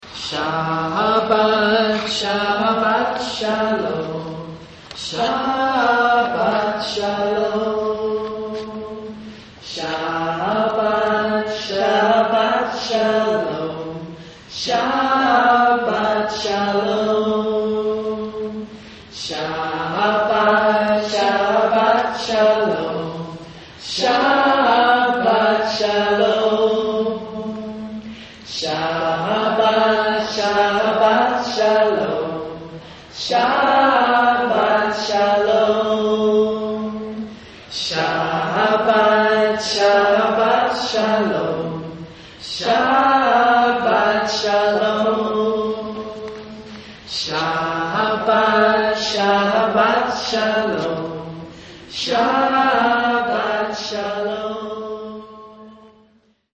For more than 15 years we met monthly in the Reutlinger Community Synagogue.